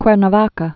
(kwĕr-nə-väkə, -nä-väkä)